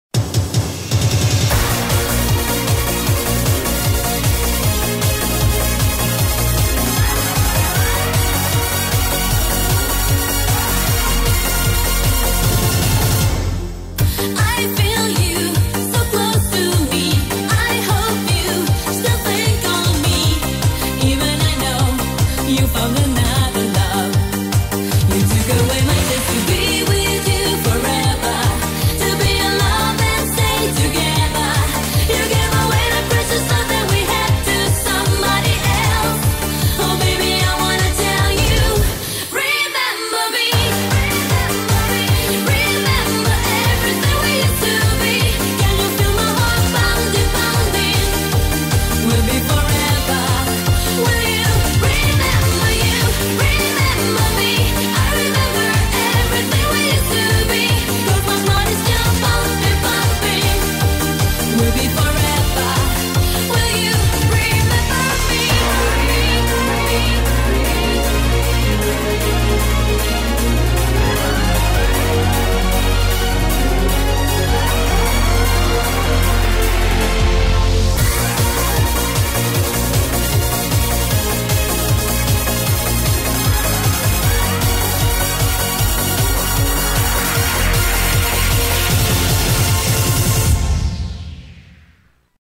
BPM153
Audio QualityPerfect (High Quality)
Get in losers. We're going to EUROBEAT!